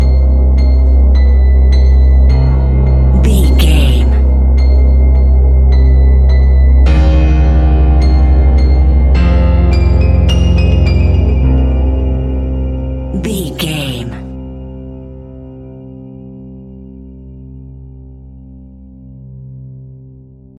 In-crescendo
Thriller
Aeolian/Minor
tension
ominous
haunting
eerie
horror music
Horror Pads
horror piano
Horror Synths